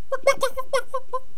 chicken_ack1.wav